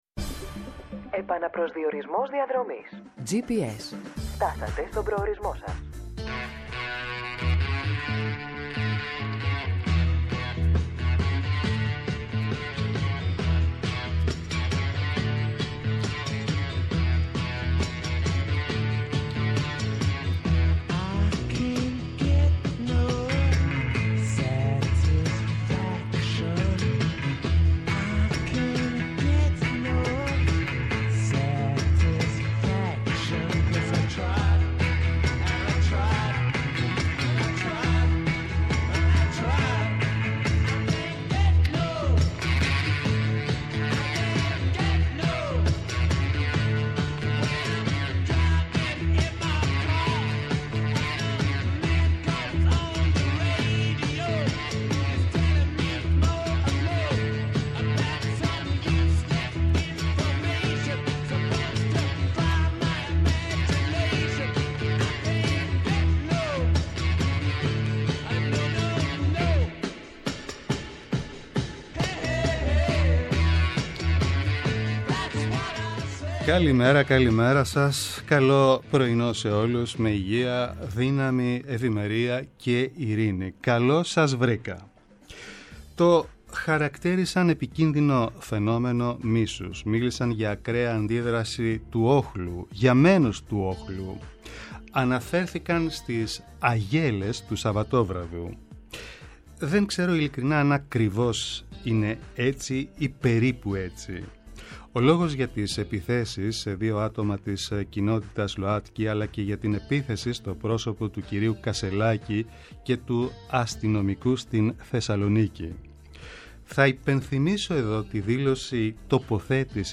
Eνα καθημερινό ραντεβού με τον παλμό της επικαιρότητας, αναδεικνύοντας το κοινωνικό στίγμα της ημέρας και τις αγωνίες των ακροατών μέσα από αποκαλυπτικές συνεντεύξεις και πλούσιο ρεπορτάζ επιχειρεί να δώσει η εκπομπή Gps